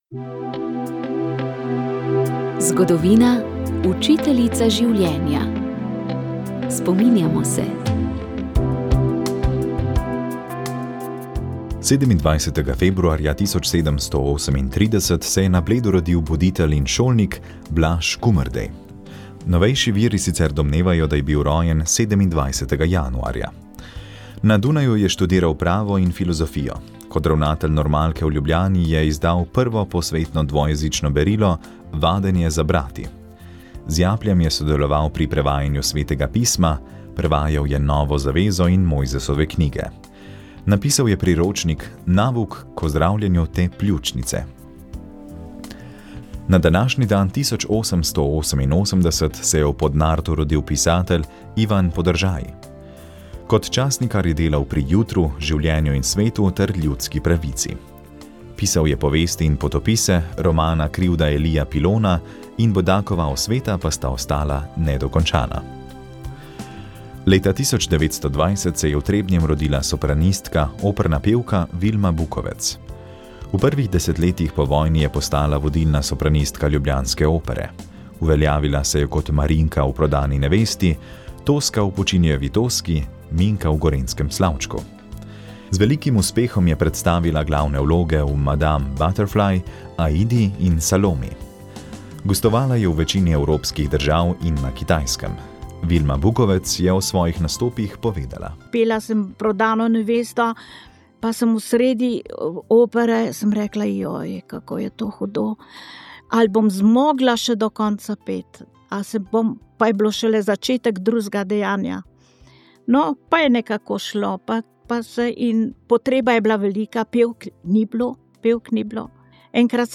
Rožni venec
Molitev je vodil ljubljansk pomožni škof Franc Šuštar.